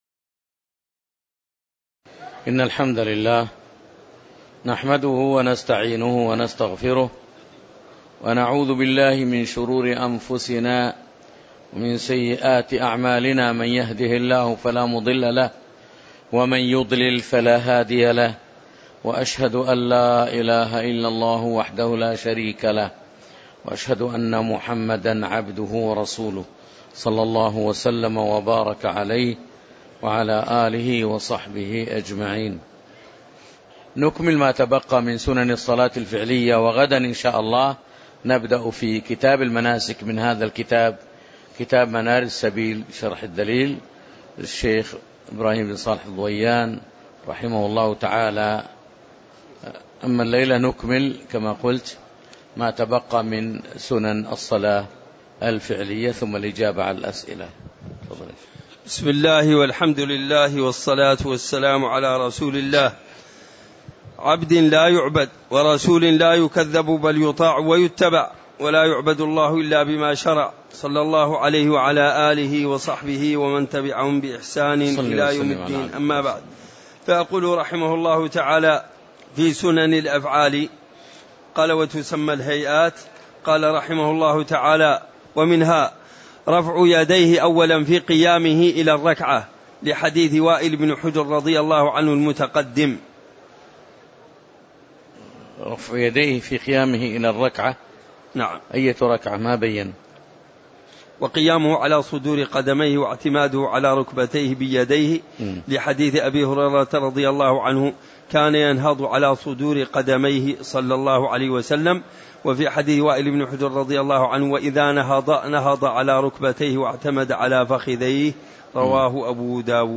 تاريخ النشر ٢٤ ذو القعدة ١٤٣٨ هـ المكان: المسجد النبوي الشيخ